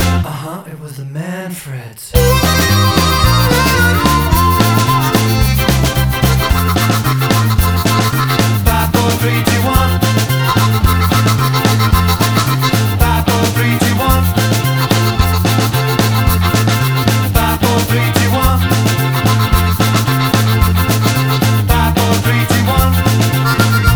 Two Semitones Down Pop (1960s) 2:57 Buy £1.50